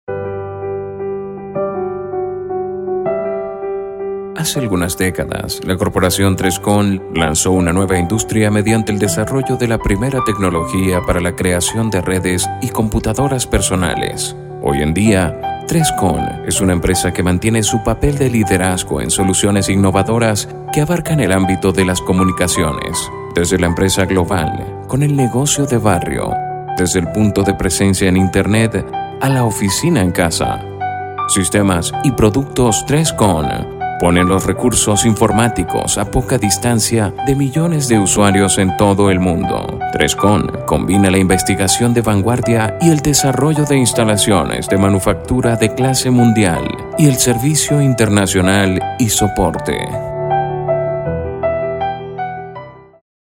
Voz comercial para su negocio o empresa con más de 18 años de experiencia dándole el tono y matiz a sus textos, para así transmitir el mensaje correcto a su futura cartera de clientes.
spanisch Südamerika
Sprechprobe: eLearning (Muttersprache):